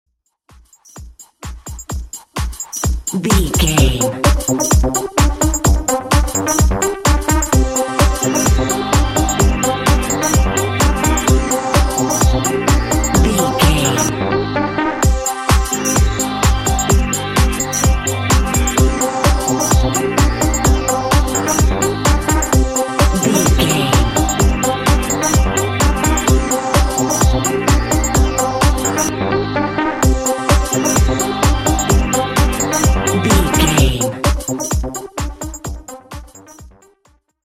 Aeolian/Minor
dark
high tech
futuristic
driving
energetic
tension
drum machine
synthesiser
house
techno
electro house
synth lead
synth bass